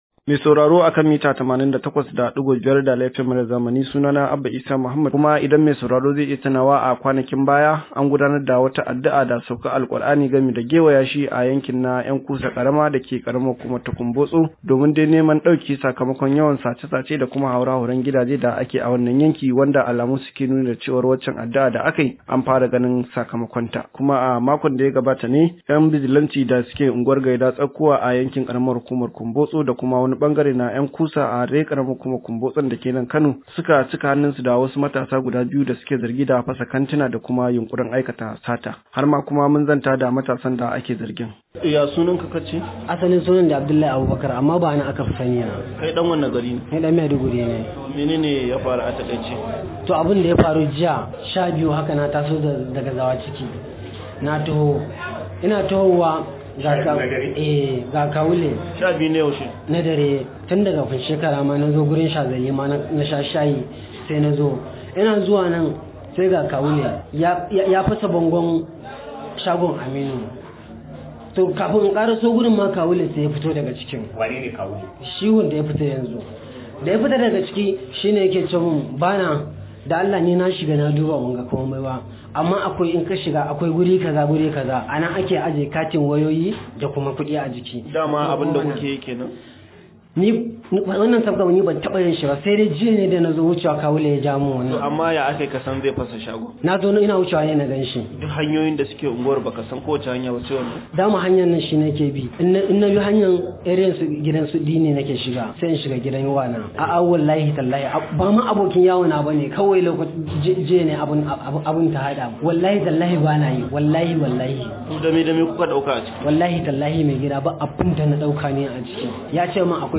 Rahoto: Bijilante ta kama matasa 2 kan zargin fasa shaguna